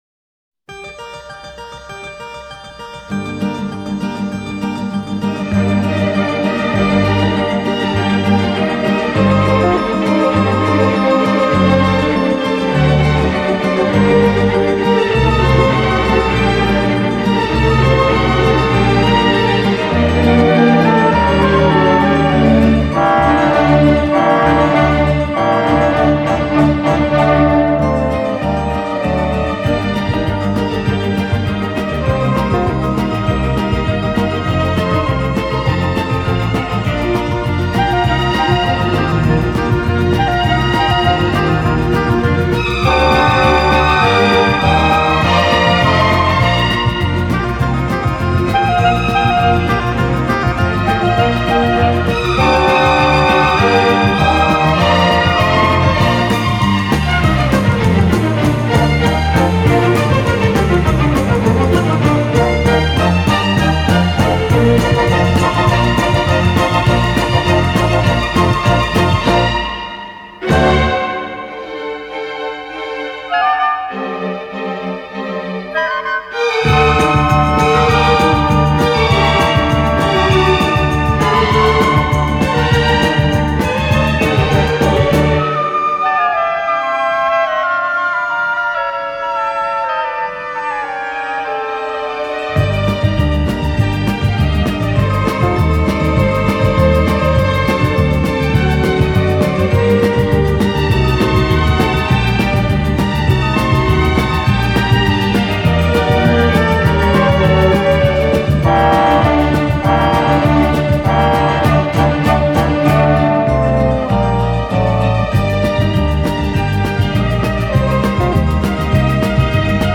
Genre:Instrumental